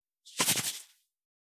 368,調味料固形物,カシャカシャ,サラサラ,
効果音厨房/台所/レストラン/kitchen
効果音